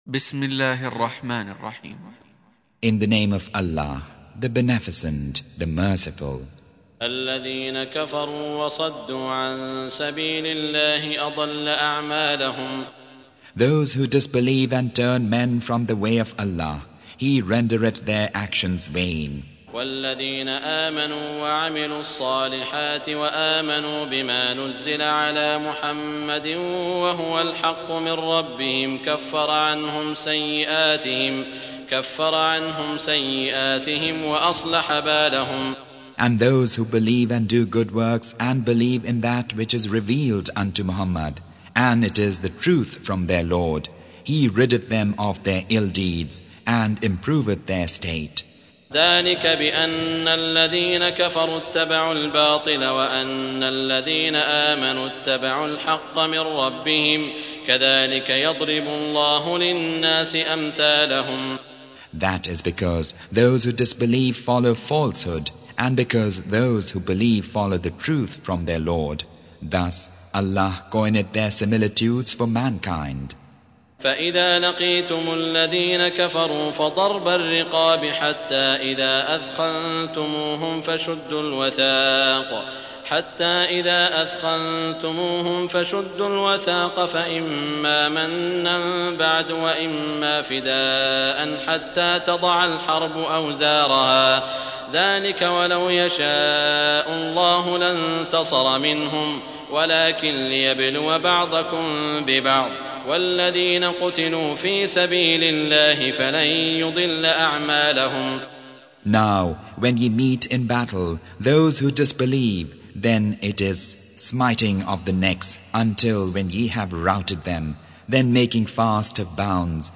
Surah Repeating تكرار السورة Download Surah حمّل السورة Reciting Mutarjamah Translation Audio for 47.